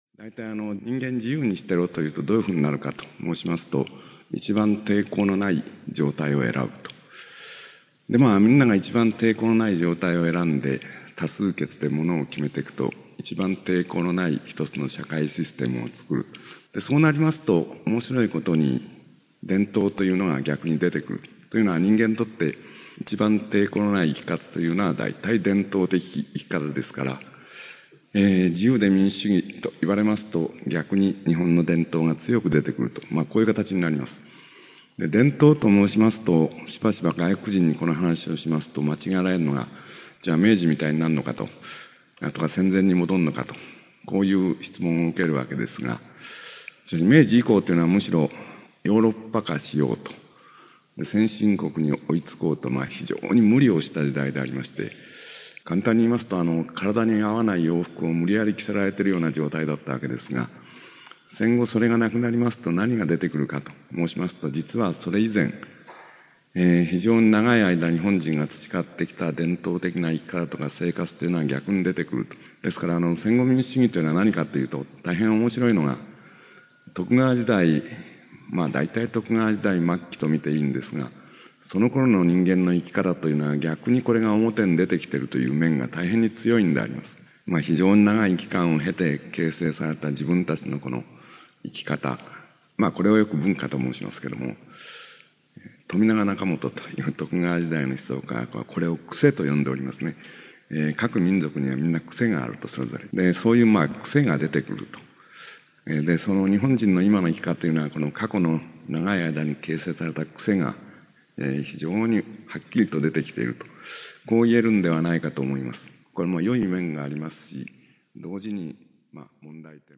[オーディオブック] 山本七平「伝統と現代」
（1988年11月26日 静岡市民文化会館 菊池寛生誕百周年記念講演会より）